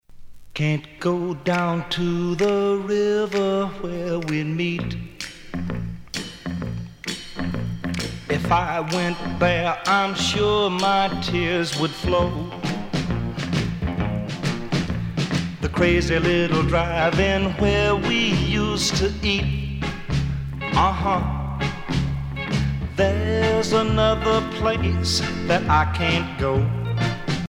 danse : rock
Pièce musicale éditée